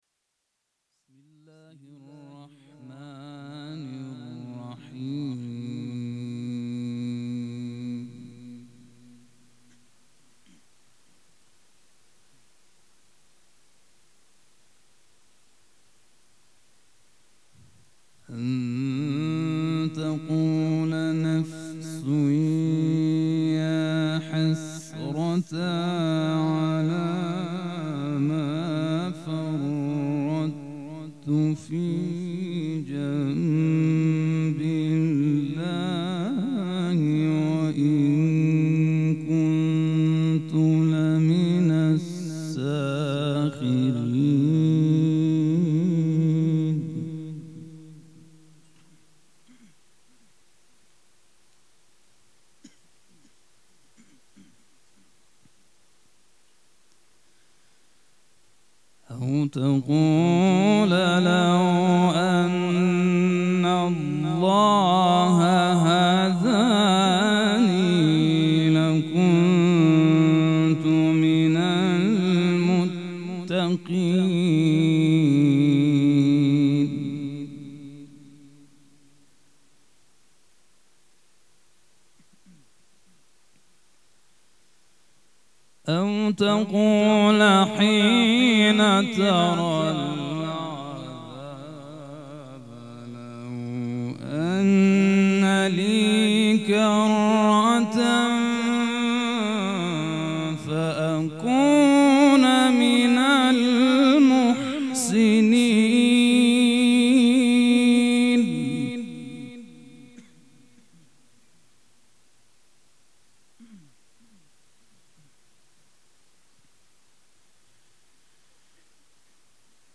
قرائت قرآن کریم
مراسم شهادت حضرت زینب کبری(سلام الله عليها)
مجتمع فرهنگی مذهبی ریحانة الحسین(س)
قرائت قرآن